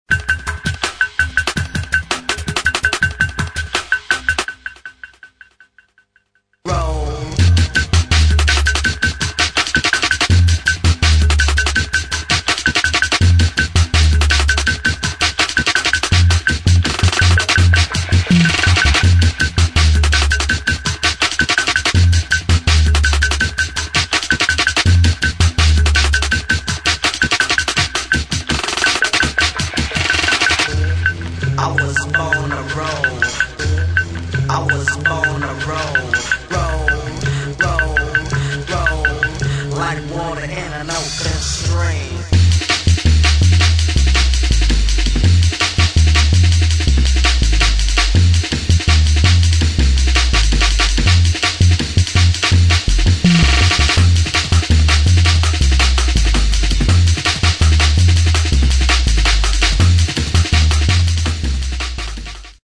[ DRUM'N'BASS / JUNGLE / OLDSKOOL ]